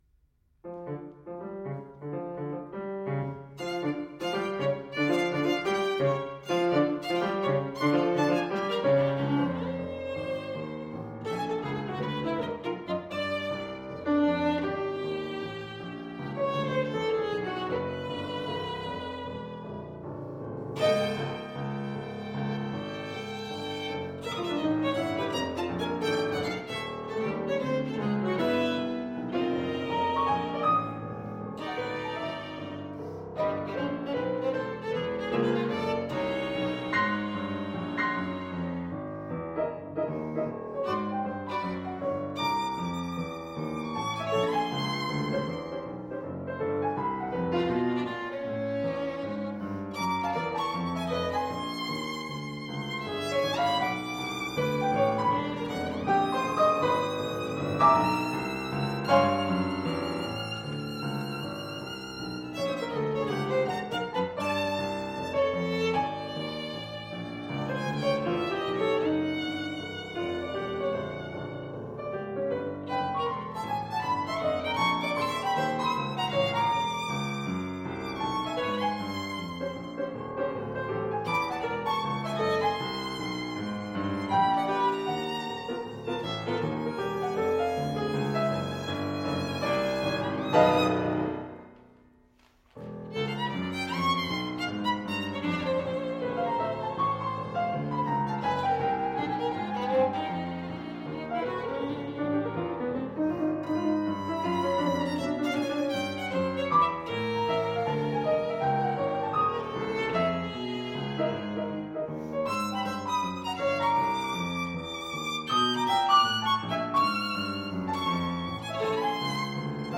Jazz
Each of the 3 Pieces… is based on the same 12-tone row.